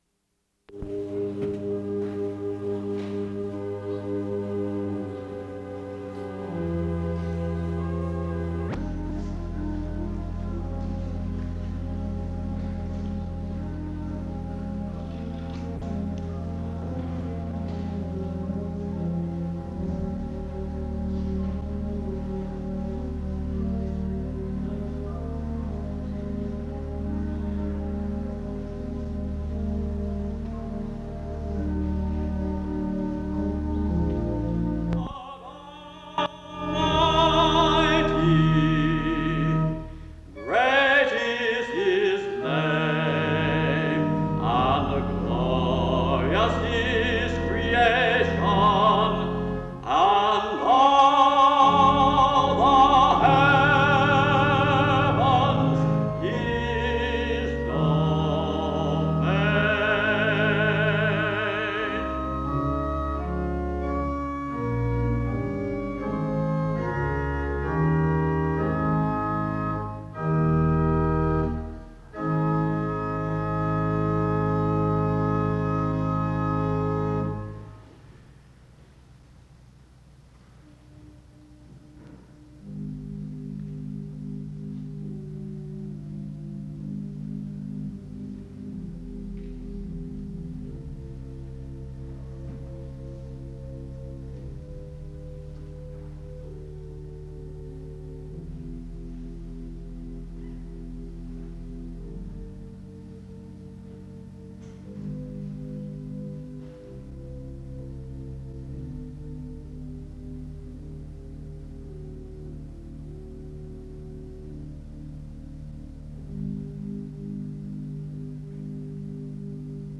facilitates this program which starts with a congregational hymn and prayer.
Subject Music Worship
Location Wake Forest (N.C.)